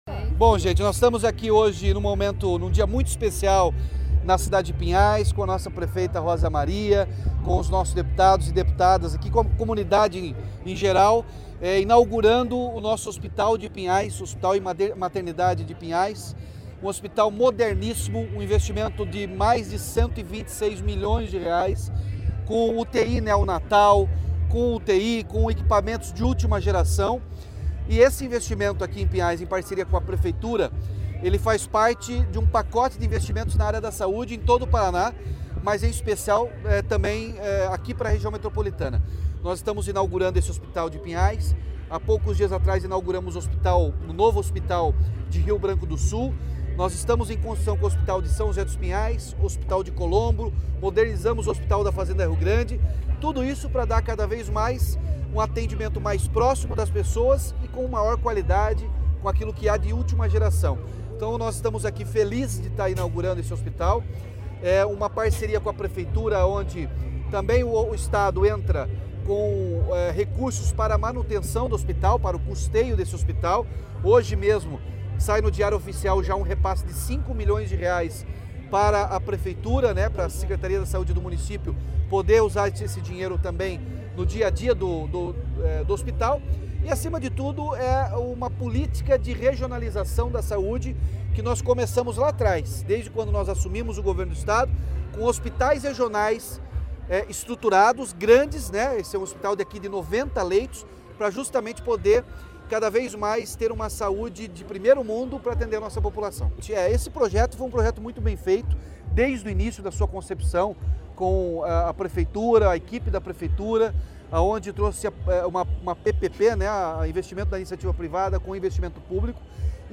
Sonora do governador Ratinho Junior sobre o novo Hospital e Maternidade Municipal Papa Francisco, em Pinhais